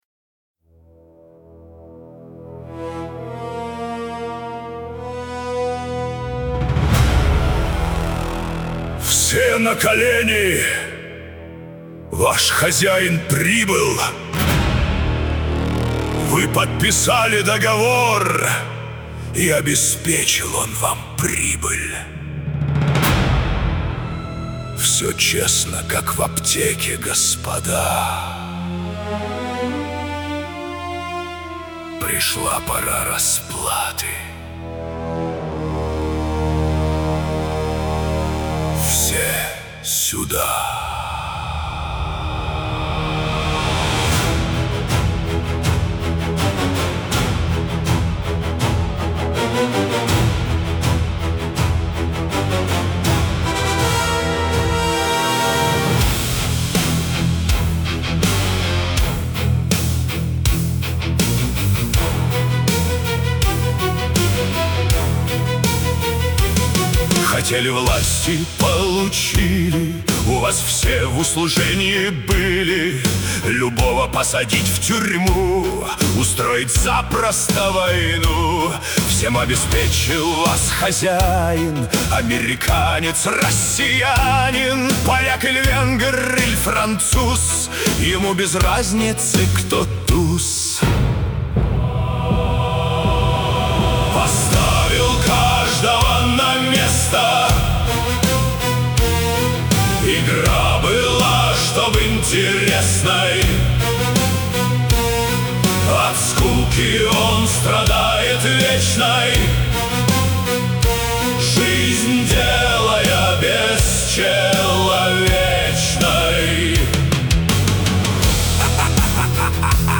mp3,9829k] Рок